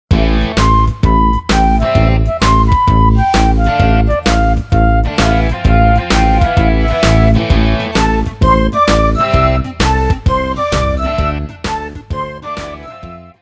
fichier midi
karaoké